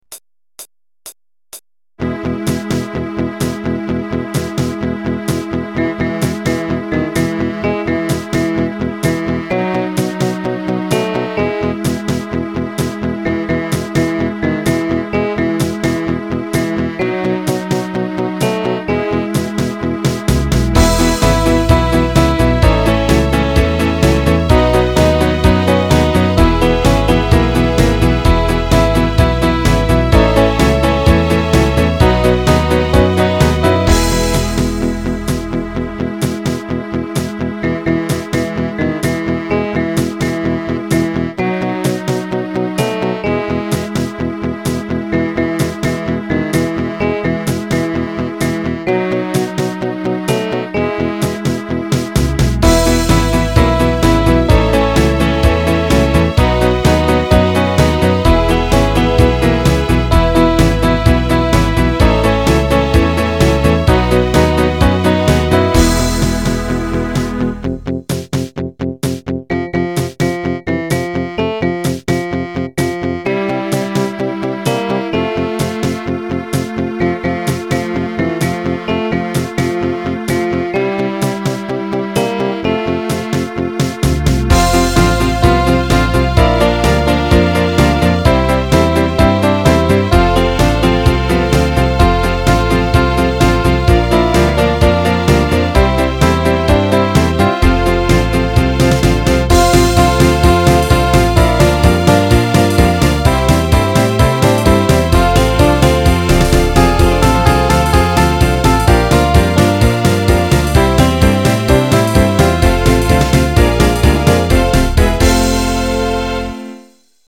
ohne "echte" Instrumente produzierte Musik